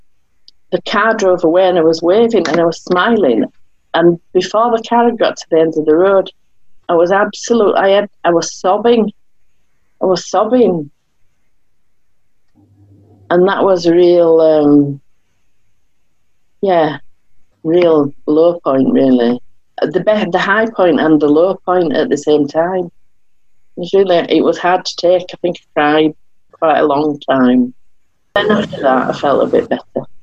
The Discover 2020 project has recorded personal stories of those who experience mental health distress, living in lockdown to create an archive of experience for future generations.